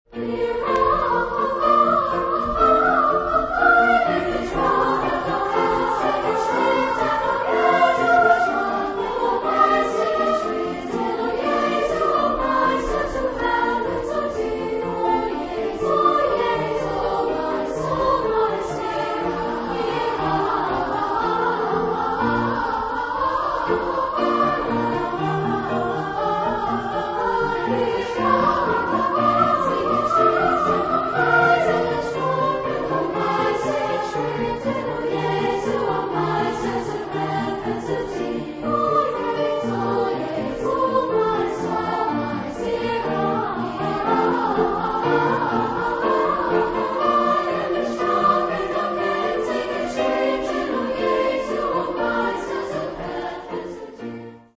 Genre-Style-Form: Sacred ; Cantata ; Duet
Type of Choir: SA  (2 women voices )
Instruments: Piano (1)
Tonality: A flat major
sung by Galway Choral Assoc. Youth Choir
Discographic ref. : Cork International Choral Festival 2006